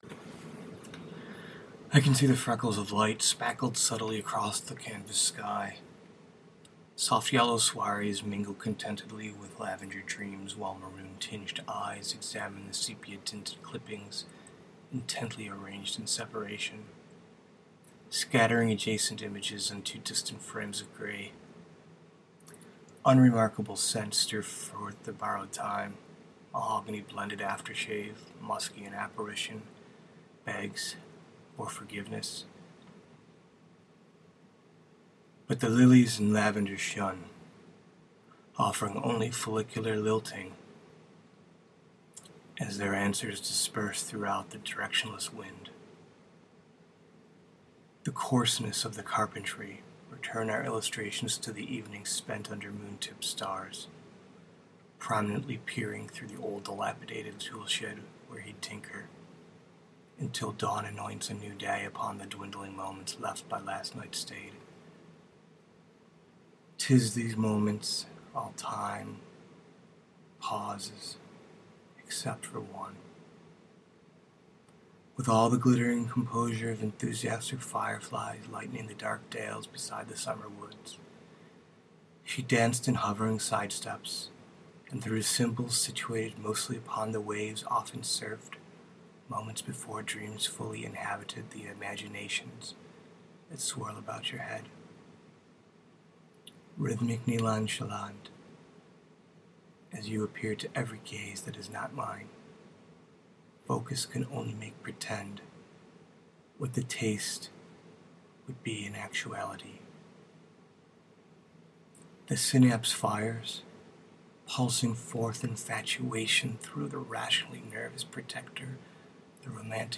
loss, senses, poetry, story, reading